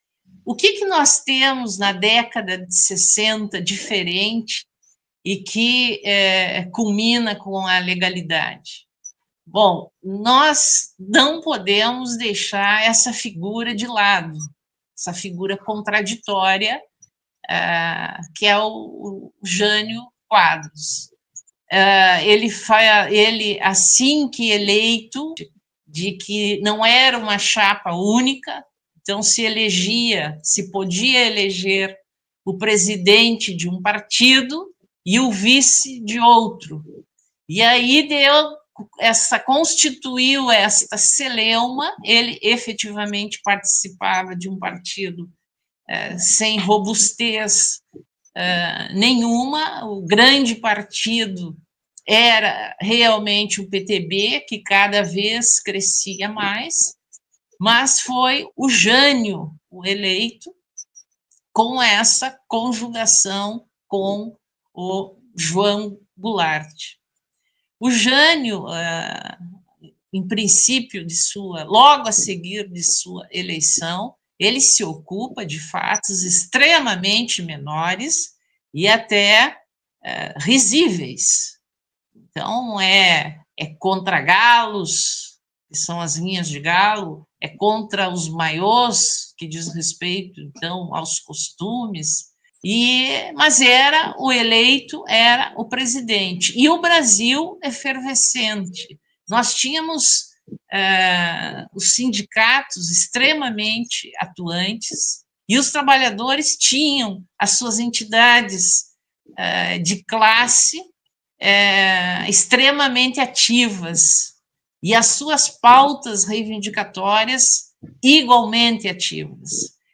foi extraída do debate realizado no canal Brasil Progressista, no Youtube.